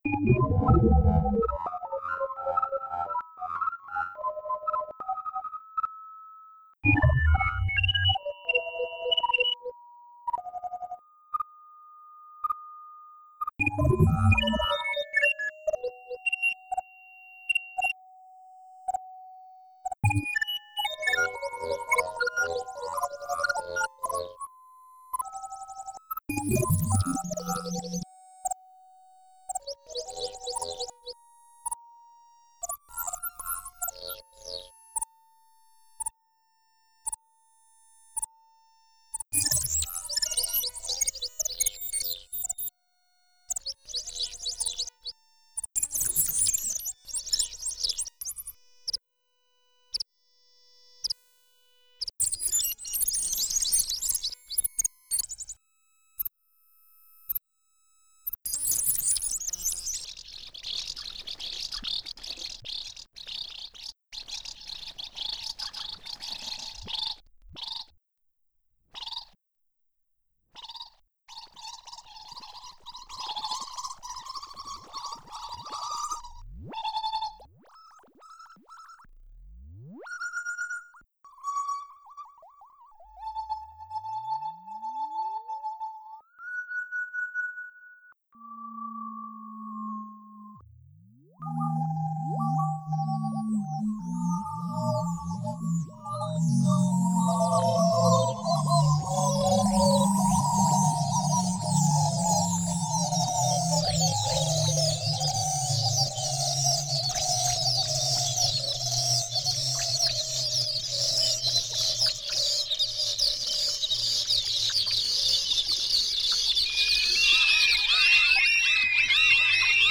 electronic music (4 channels)